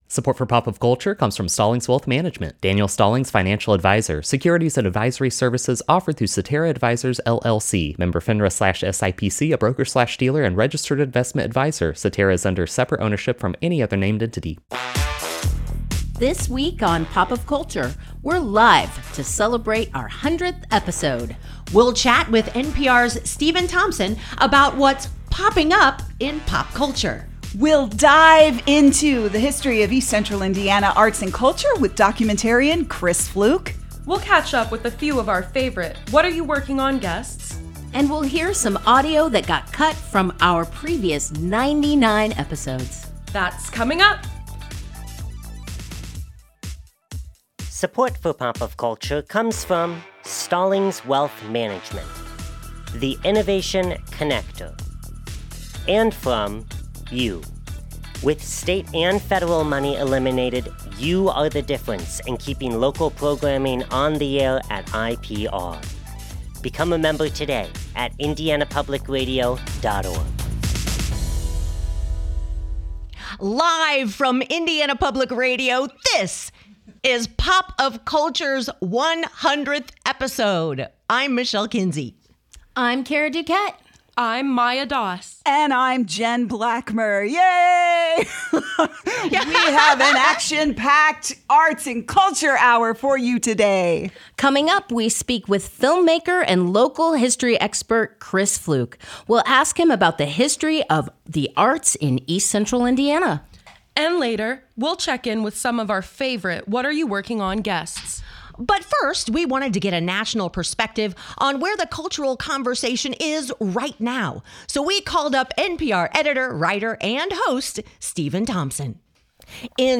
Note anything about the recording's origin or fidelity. This week, we’re celebrating our 100th episode by doing the show live!